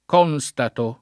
constatare v.; constato [konSt#to o